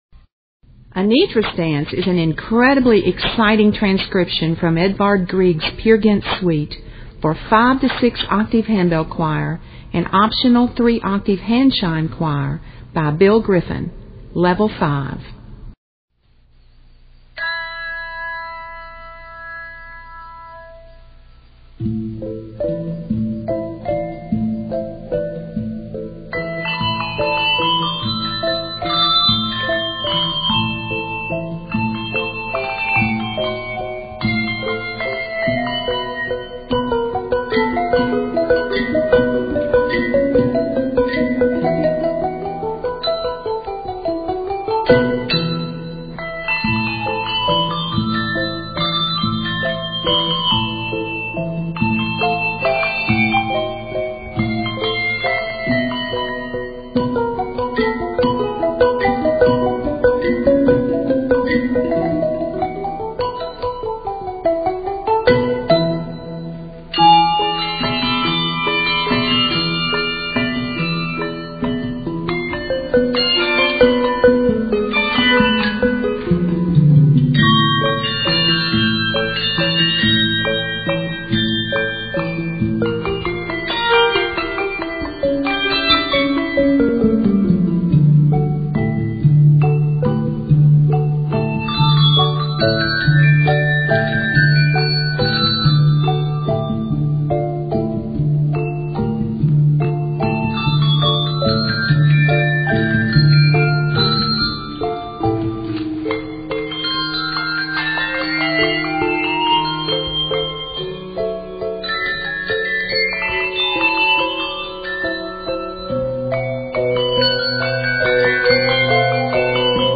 bell transcription
A total of 108 measures, it is scored in a minor.
Octaves: 5-6